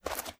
STEPS Dirt, Walk 25.wav